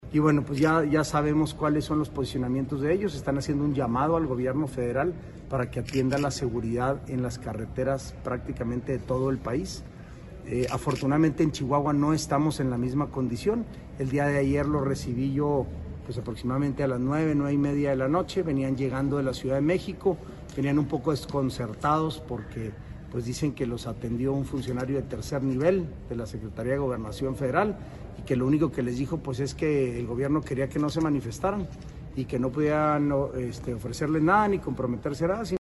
AUDIO: SANTIAGO DE LA PEÑA, TITULAR DE LA SECRETARÍA GENERAL DE GOBIERNO (SGG)